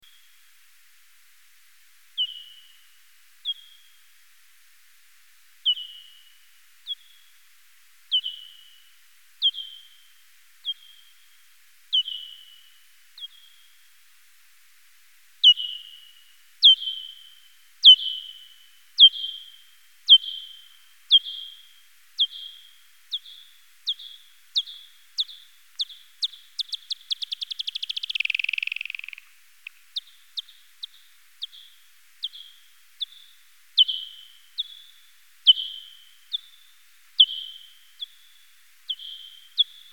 Gould’s Wattled Bat – Full spectrum bat calls, NSW, Australia
Chalinolobus gouldii CHGO
Characteristic frequency between 25 and 34 kHz. Consecutive pulses often alternate in frequency and sometimes shape when in “typical” search phase. The upper pulses may drop out when “cruising” in open spaces leaving only the lower pulses.